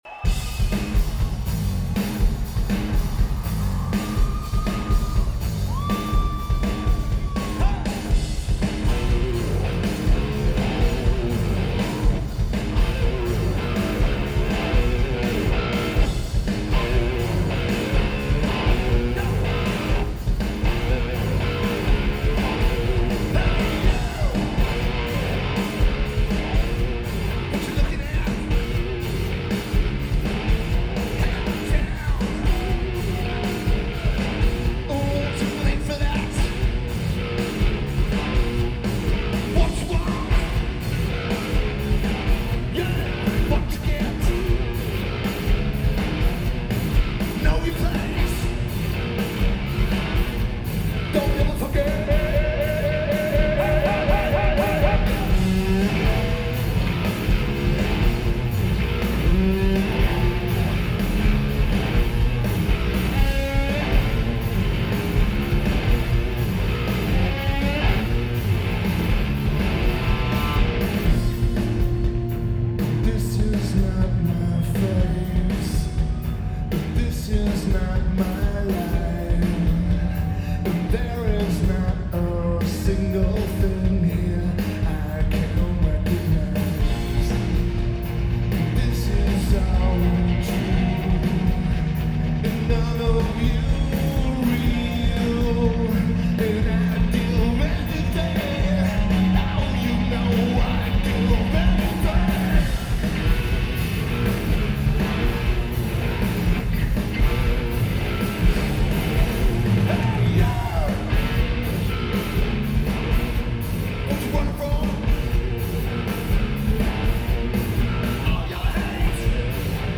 Verizon Wireless Amphitheater
Lineage: Audio - AUD (DPA 4023s + Sound Devices 722)